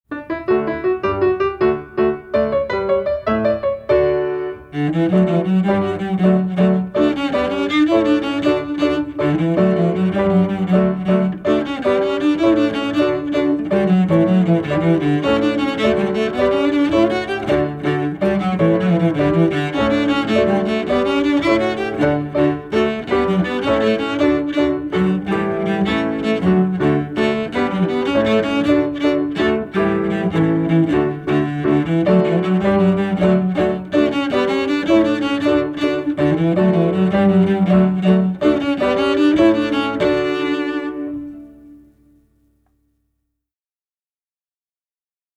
Voicing: Cello